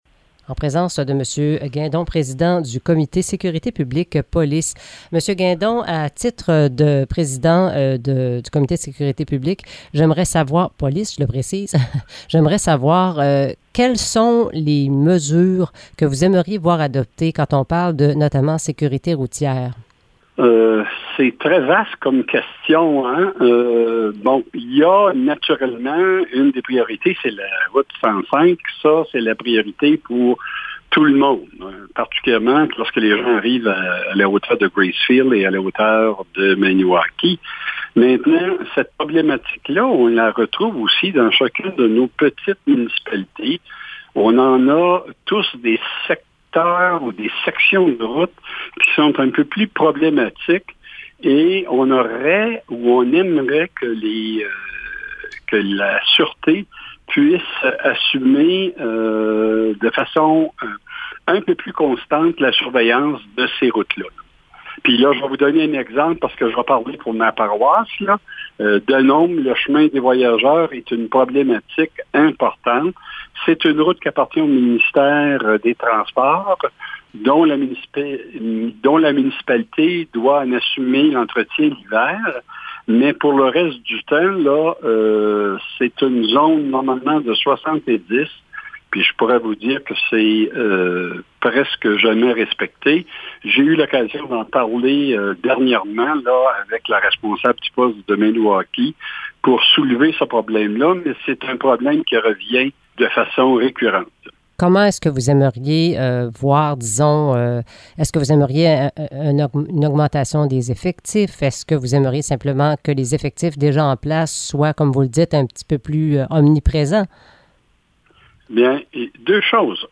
Entrevue avec Gaétan Guindon, maire de Denholm et président du comité de sécurité publique - section police - à la MRC Vallée-de-la-Gatineau